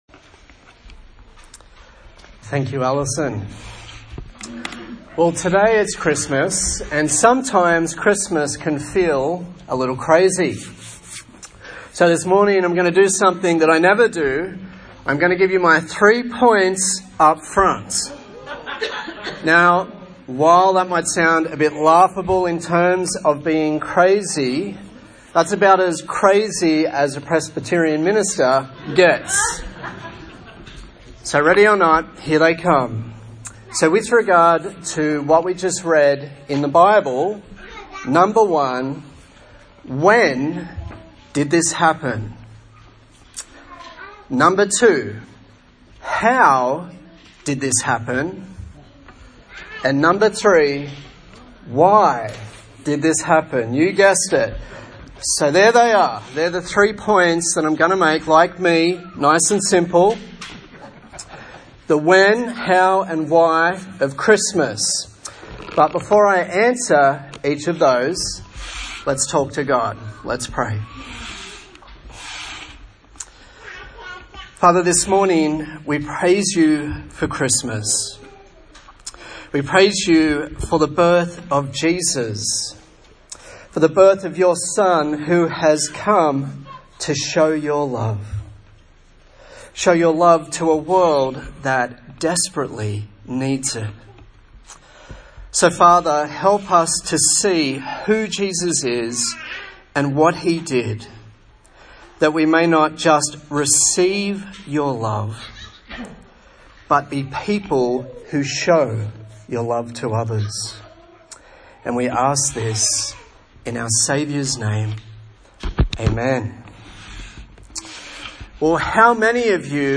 Service Type: Christmas Day